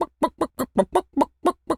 Animal_Impersonations
chicken_cluck_bwak_seq_14.wav